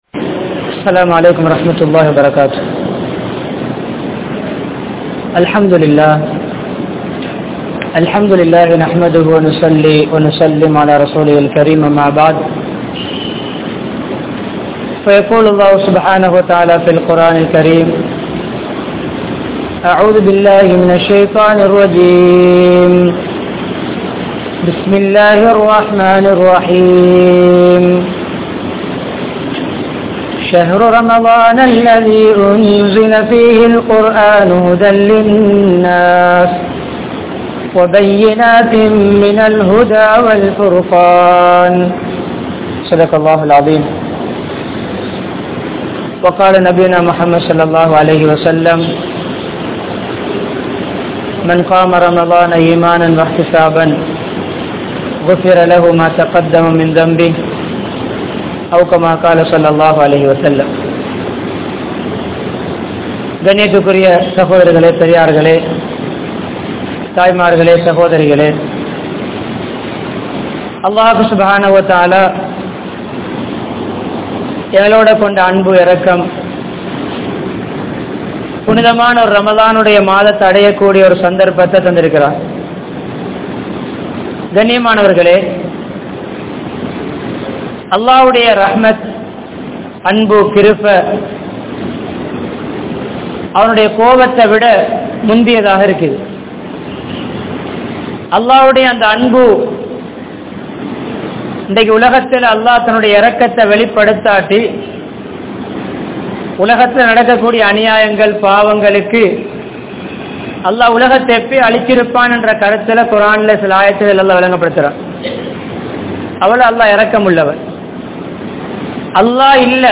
Vaalkaiyai Thiruththum Ramalan (வாழ்க்கையை திருத்தும் ரமழான்) | Audio Bayans | All Ceylon Muslim Youth Community | Addalaichenai
Colombo 15, Mattakkuliya, Kandauda Jumua Masjidh